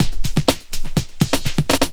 21 LOOP08 -R.wav